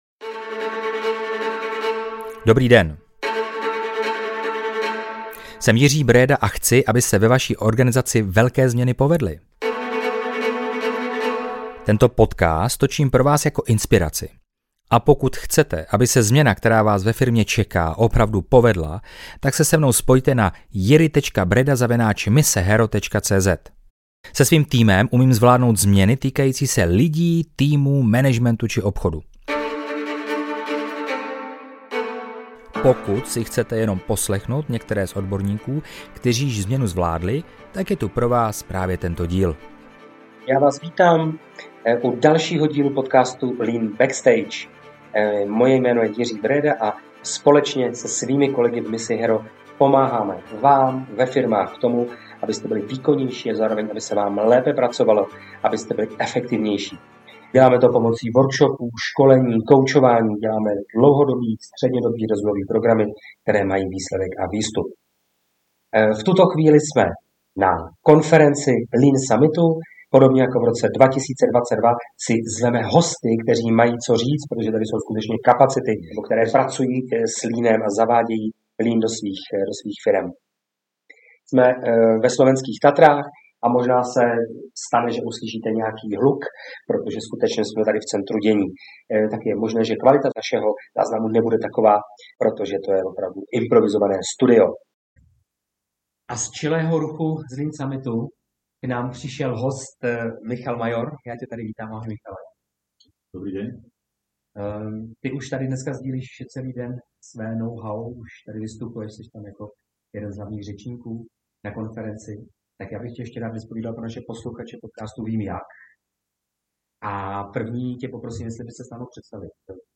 Disclaimer: Podcast byl natáčen v terénu na konferenci, kvůli čemuž je kvalita zvuku horší, než na kterou jste u nás zvyklí.